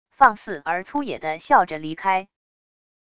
放肆而粗野地笑着离开wav音频